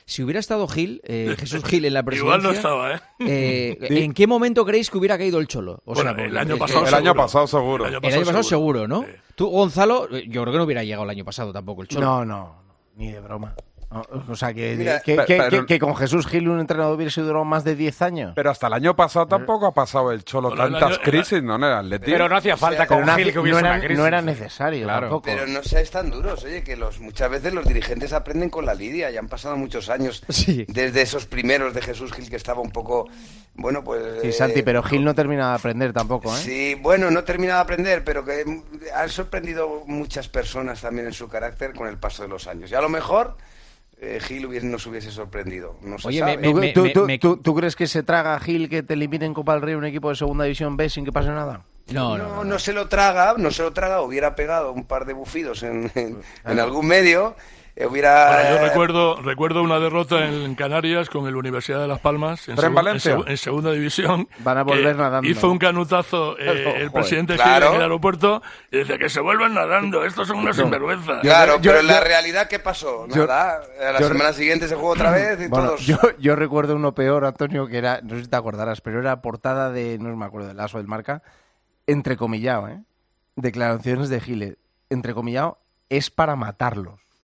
Pero en el día en que se ha conocido la gran noticia, Juanma Castaño quiso ponerle un poco de picante al Tiempo de Opinión de este jueves en El Partidazo de COPE planteando al equipo de comentaristas: si hubiera estado Jesús Gil aún en la presidencia del Atlético de Madrid, ¿en qué momento habría caído el Cholo?
La pregunta lanzada por Juanma Castaño en El Partidazo de COPE produjo algunas sonrisas.